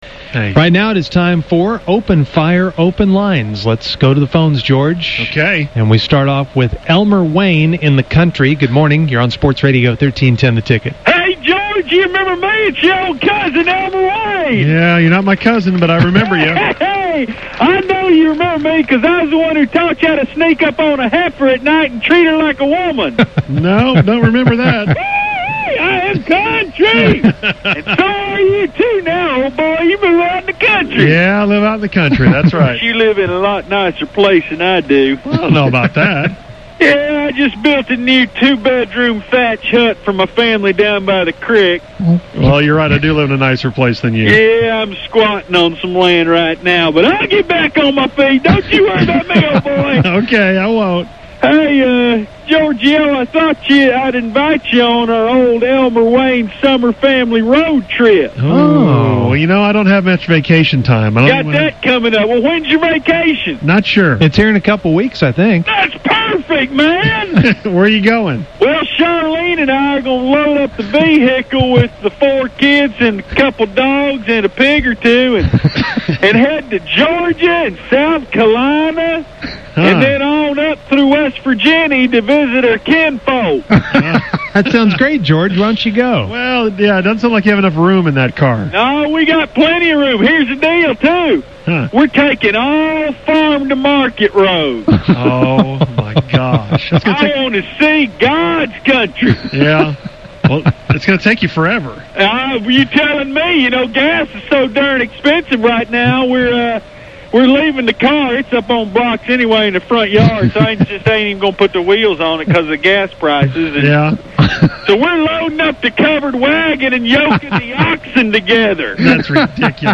Musers opened the phone lines this morning